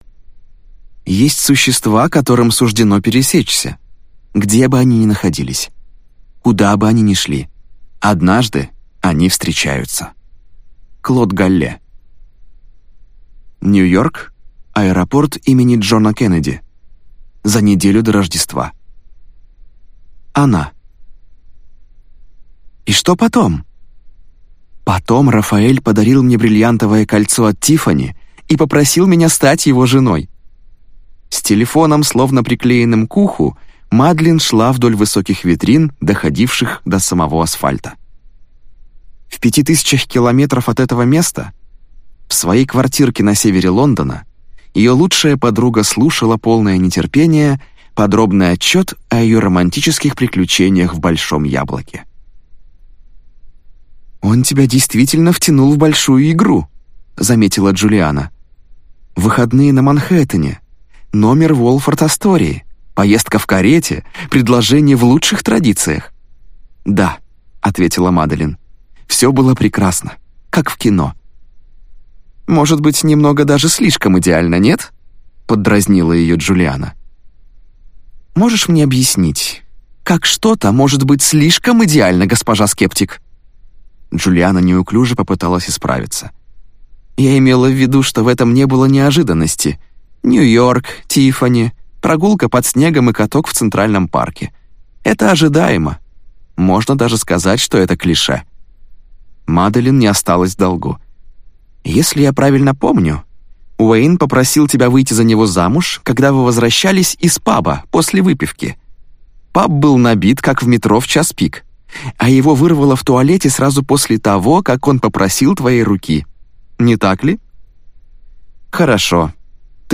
Аудиокнига Зов ангела | Библиотека аудиокниг